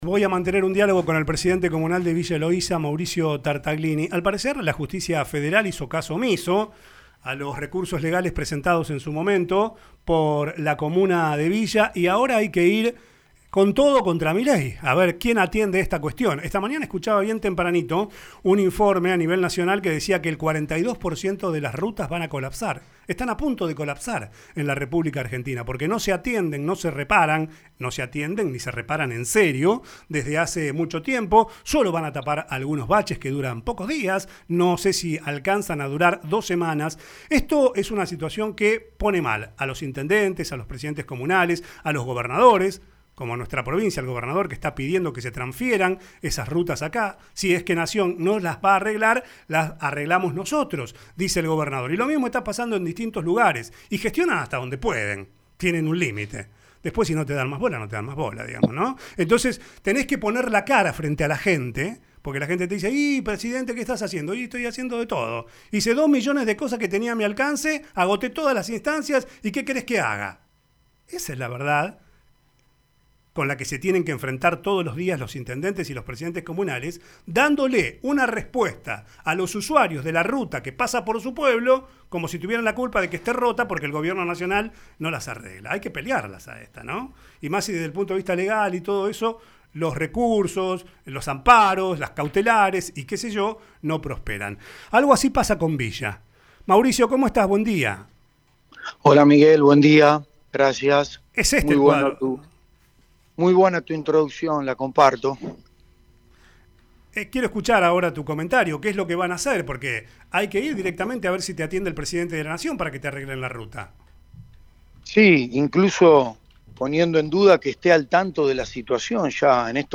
El Presidente Comunal de Villa Eloísa, Mauricio Tartaglini, renovó el pedido a Vialidad Nacional para que se realicen las tareas de reparación correspondientes en la traza.